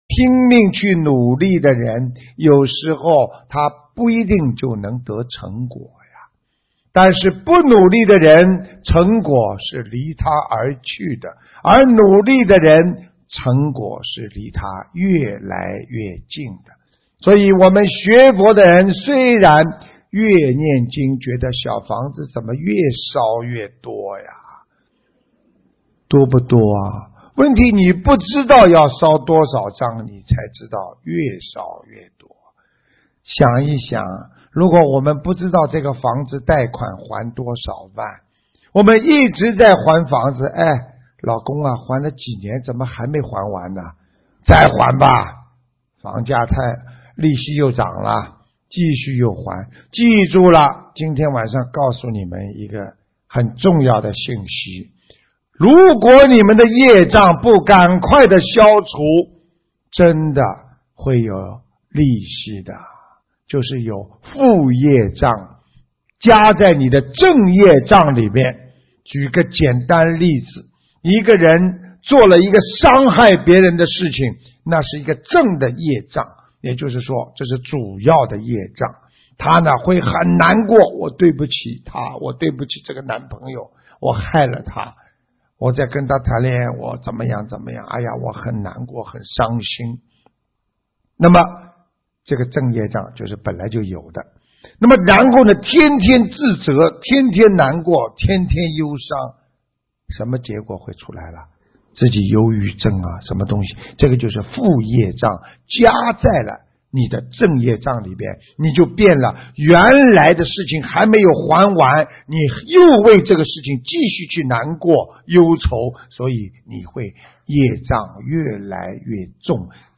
——新加坡观音堂开示150412